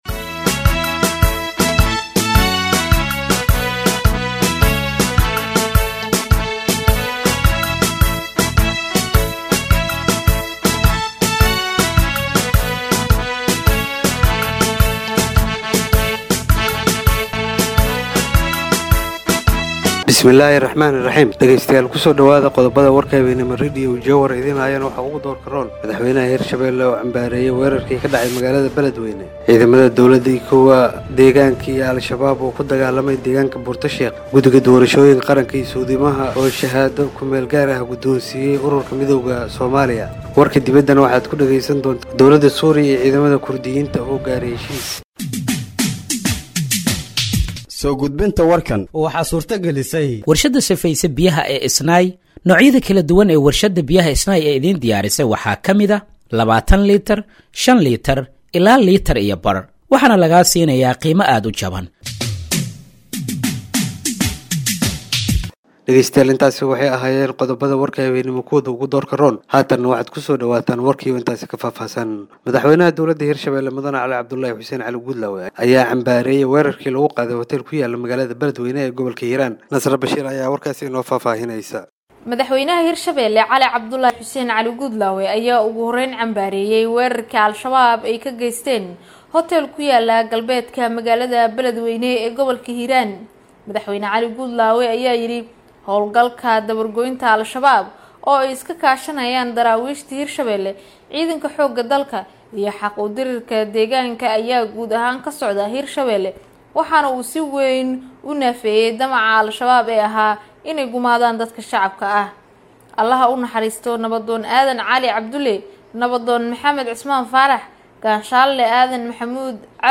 Dhageeyso Warka Habeenimo ee Radiojowhar 11/03/2025